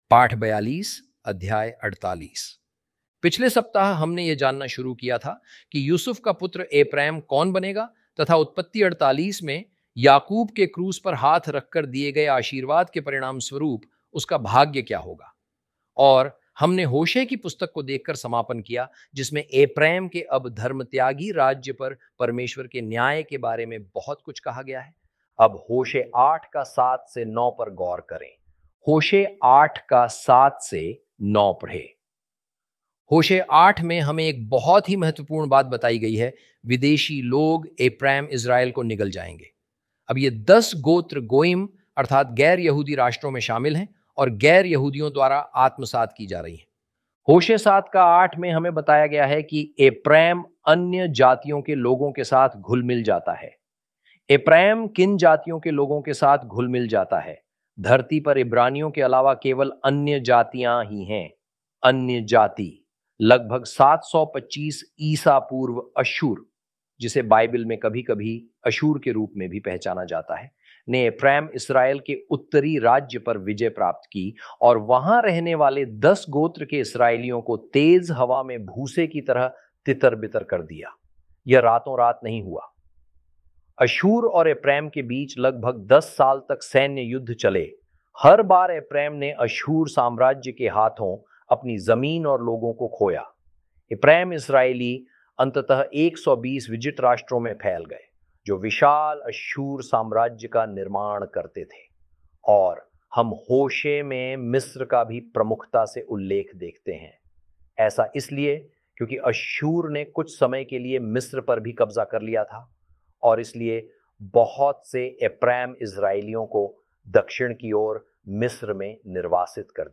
hi-audio-genesis-lesson-42-ch48.mp3